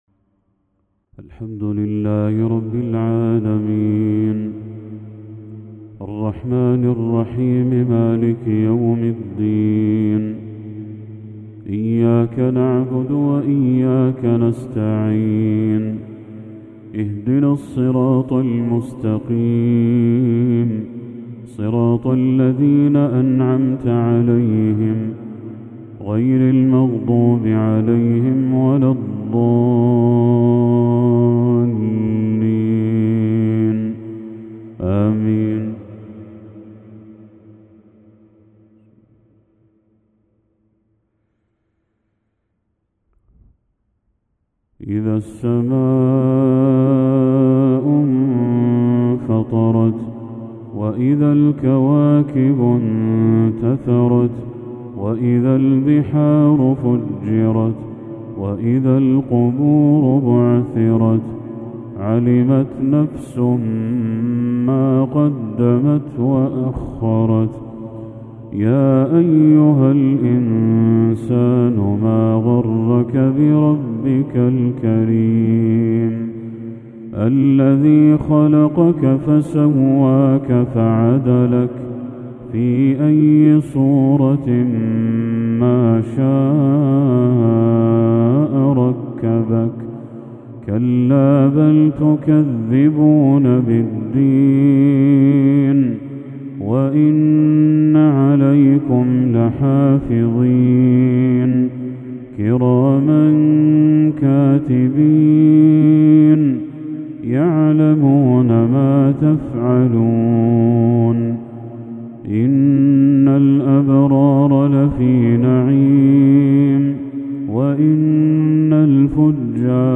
تلاوة ندية للشيخ بدر التركي سورتي الانفطار والمسد | مغرب 3 محرم 1446هـ > 1446هـ > تلاوات الشيخ بدر التركي > المزيد - تلاوات الحرمين